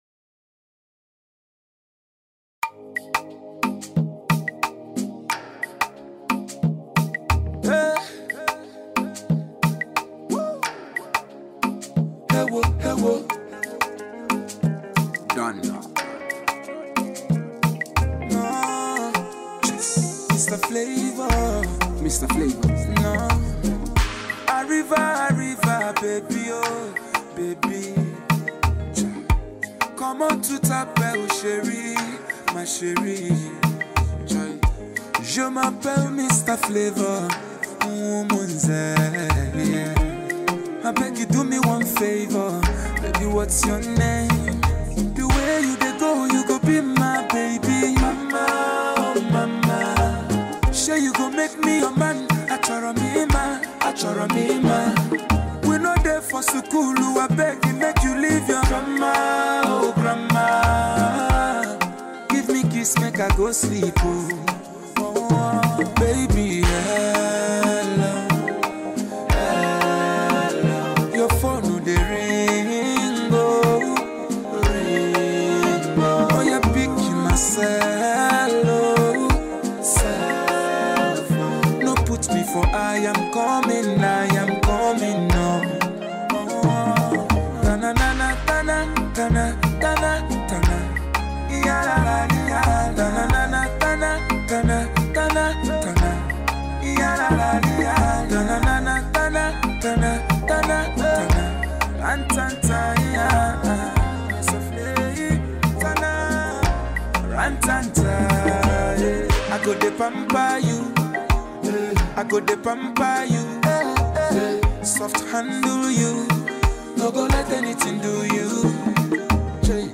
Nigerian highlife king
melodious single
lovely-dovey tune